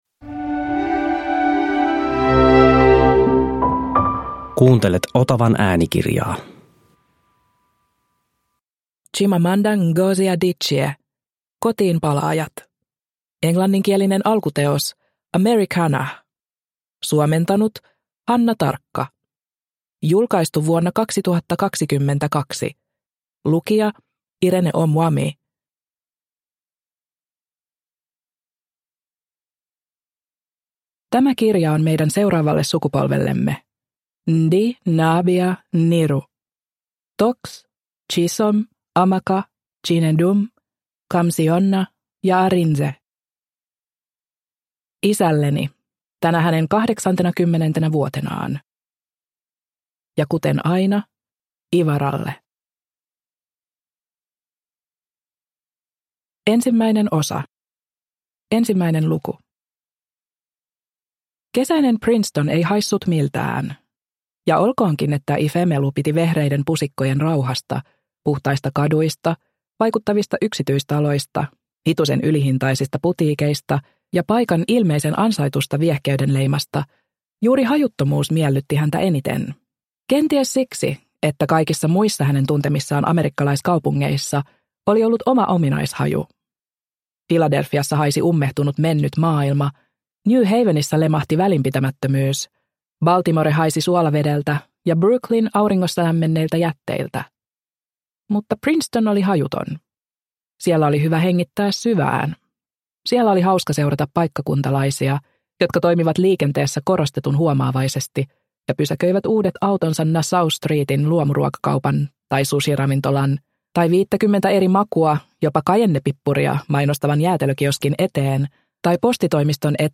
Kotiinpalaajat – Ljudbok – Laddas ner